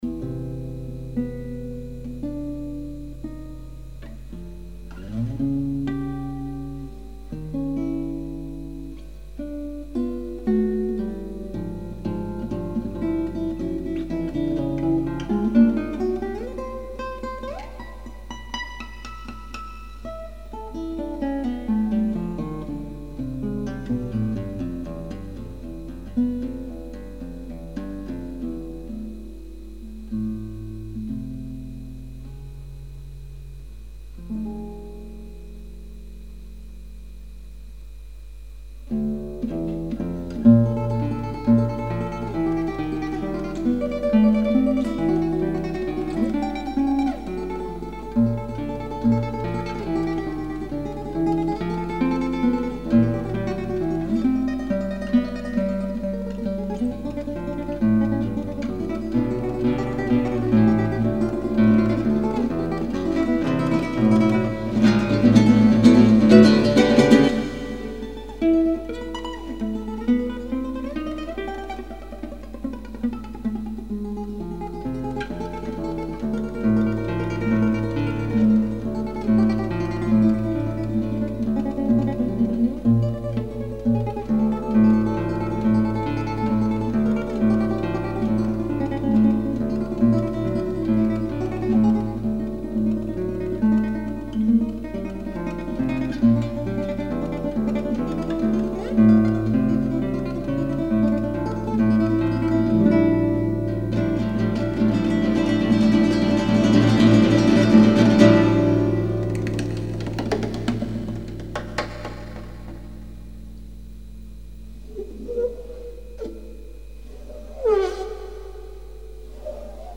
Audios Contemporáneos